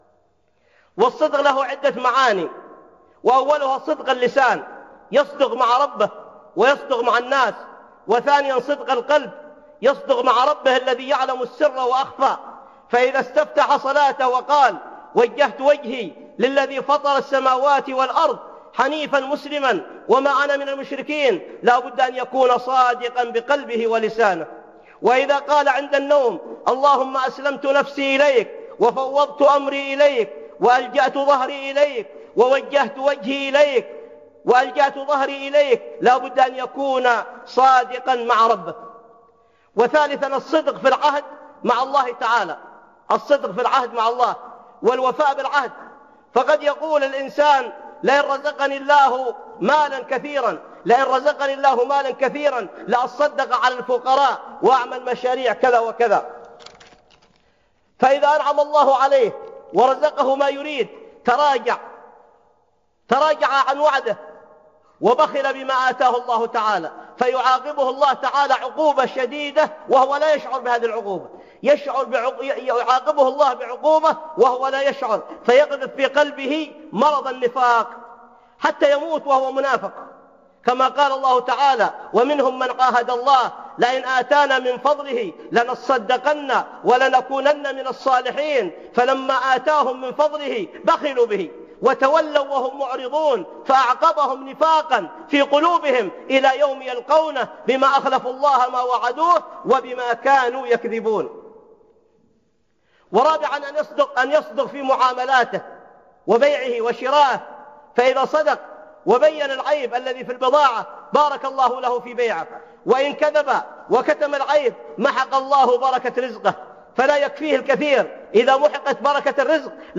من أنواع الصدق - خطب